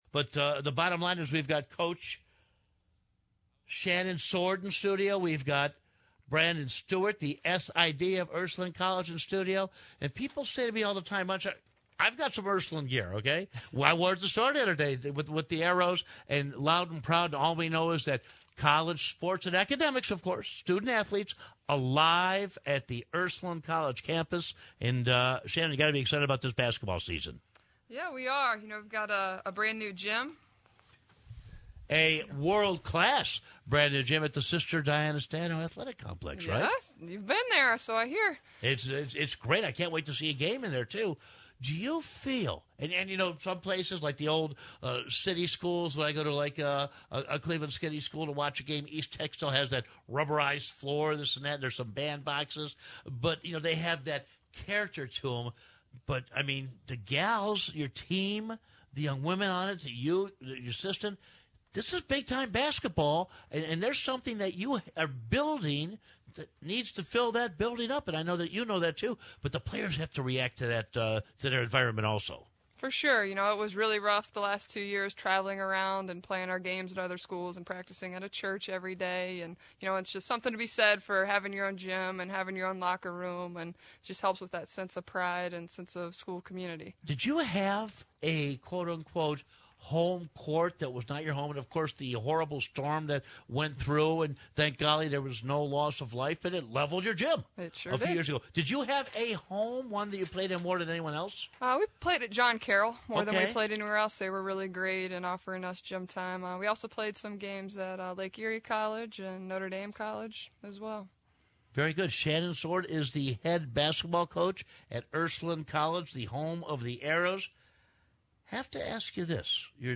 Ursuline_College_Interview_on_ECT.mp3